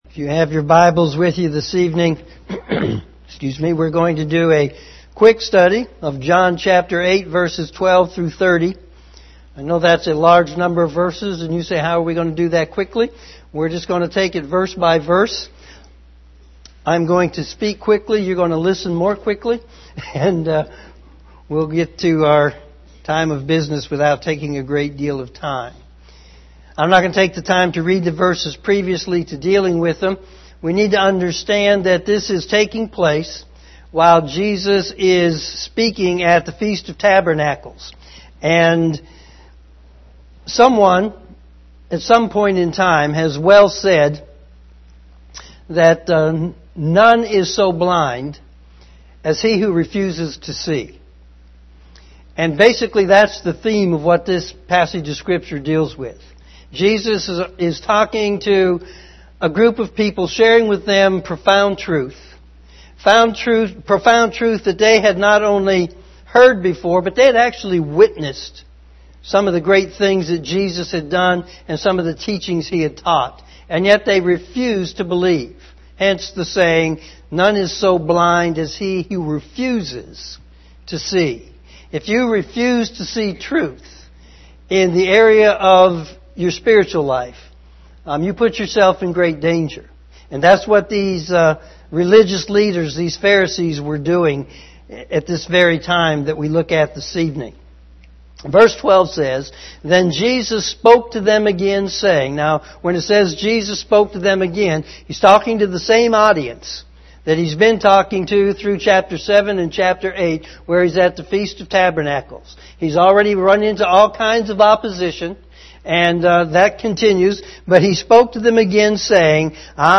evening service
sermon4-18-18pm.mp3